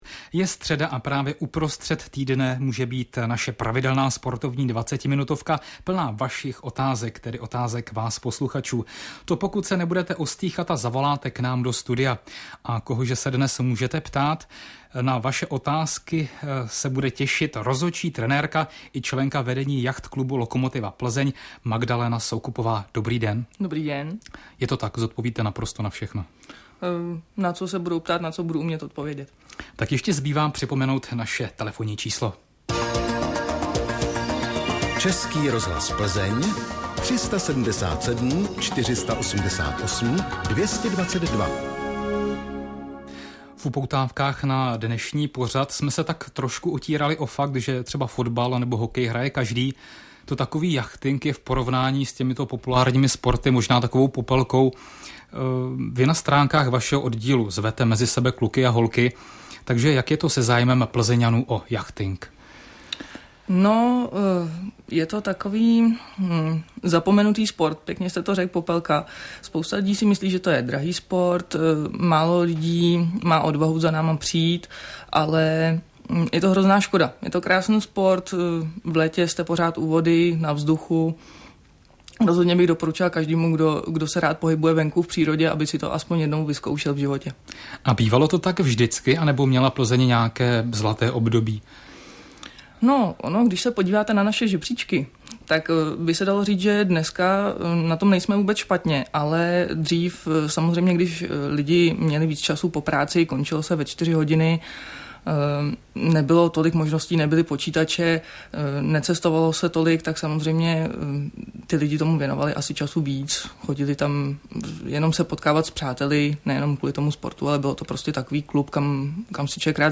Na středeční podvečer si mne pozvali do Sportovního magazínu Českého rozhlasu Plzeň, abych v krátkosti pohovořila o jachtingu a našem klubu. Záznam rozhovoru si můžete poslechnout ZDE.